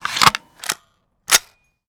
minigun_reload_01.wav